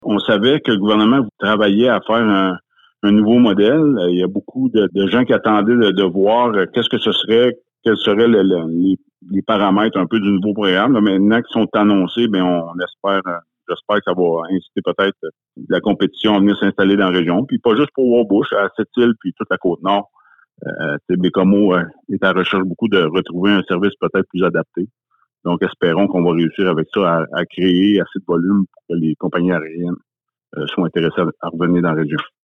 Écoutez l’entrevue complète réalisée avec Martin St-Laurent, maire de Fermont :